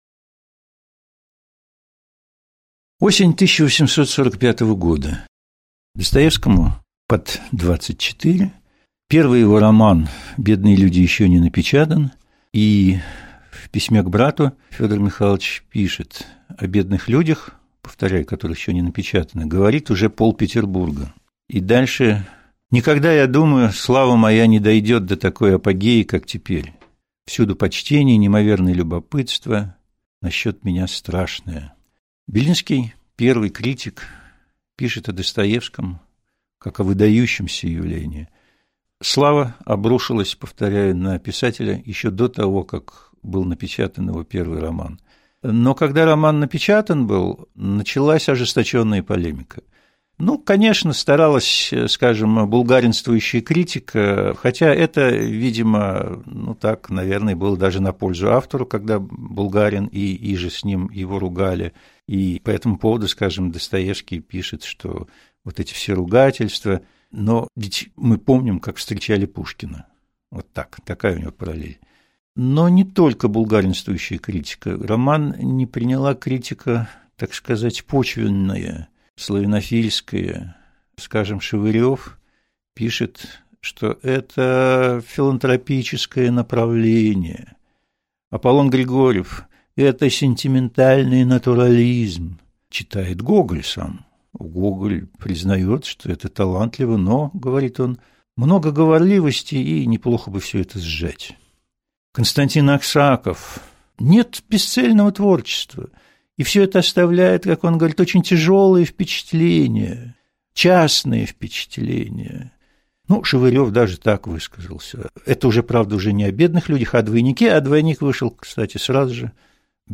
Аудиокнига Лекция «Судьба Достоевского» | Библиотека аудиокниг